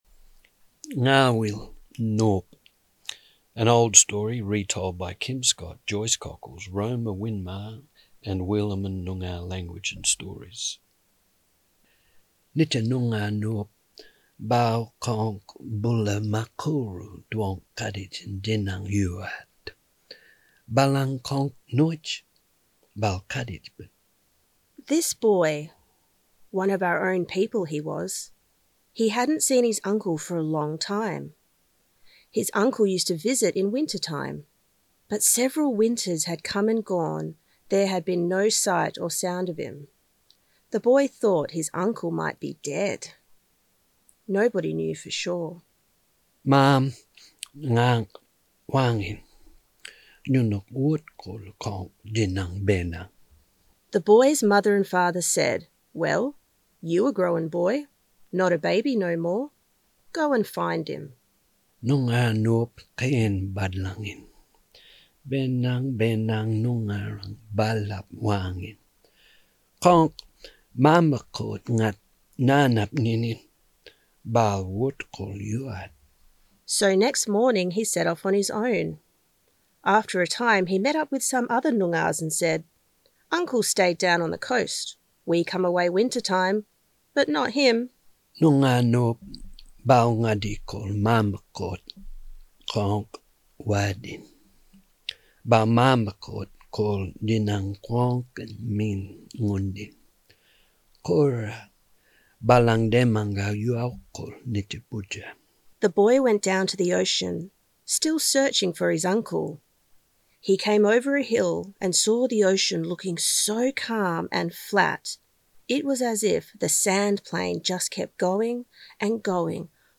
NgaawilyNopReadingEdit.mp3